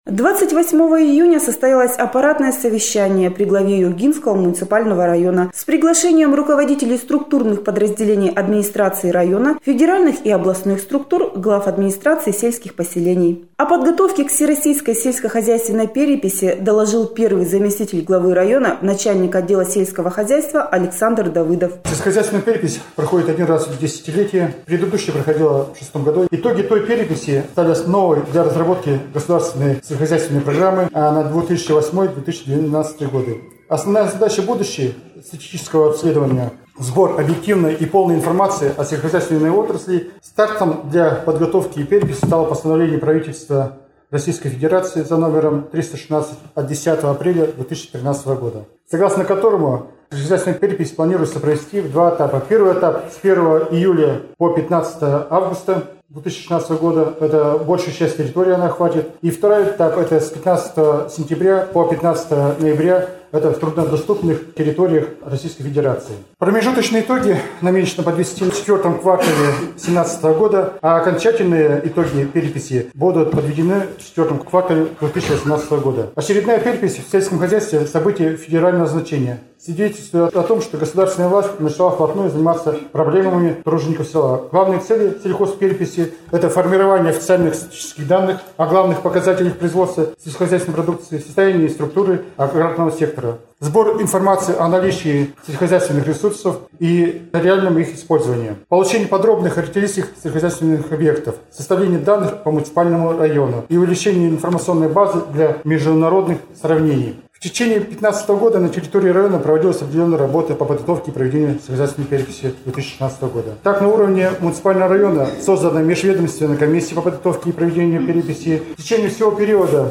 Всероссийская сельскохозяйственная перепись стоит на особом контроле администрации района. На аппаратном совещании, прошедшем на этой неделе, о подготовке Юргинского района к проведению переписи доложил первый заместитель главы района А.А. Давыдов.